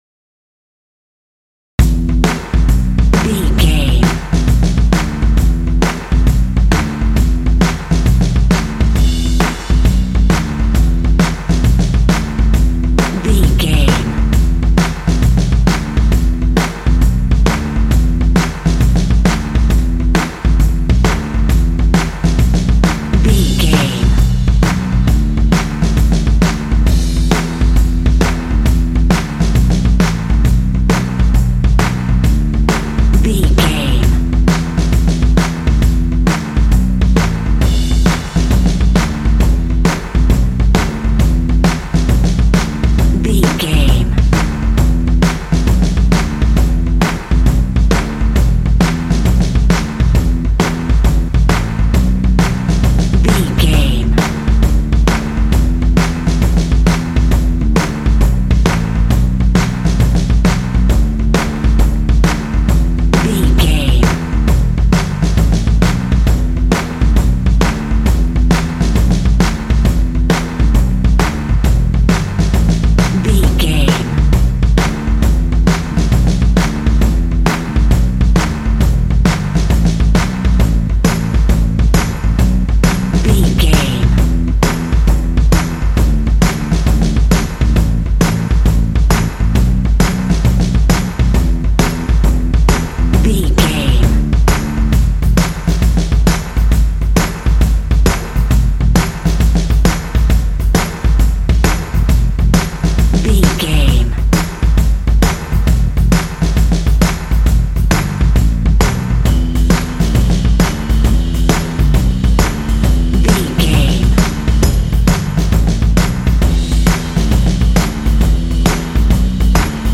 Aeolian/Minor
groovy
intense
energetic
drums
percussion
bass guitar
classic rock
alternative rock